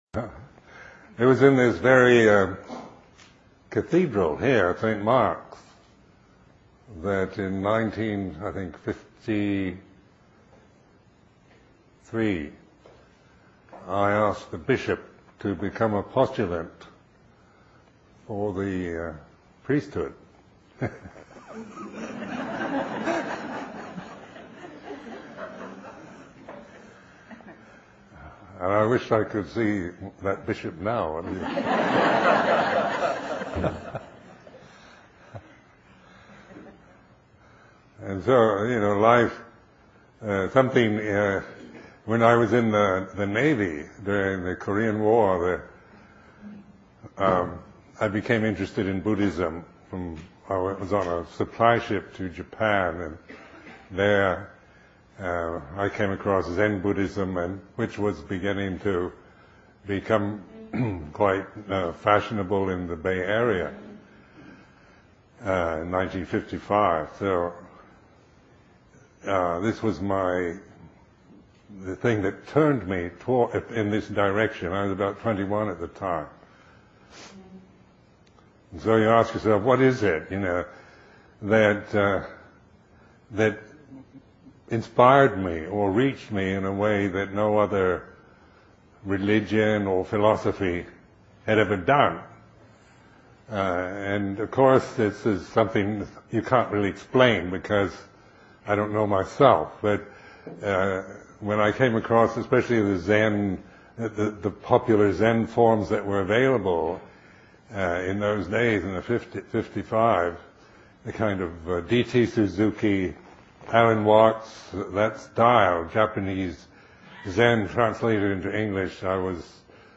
Teacher: Ajahn Sumedho Date: 2008-07-08 Venue: Seattle Insight Meditation Center Series [display-posts] TalkID=369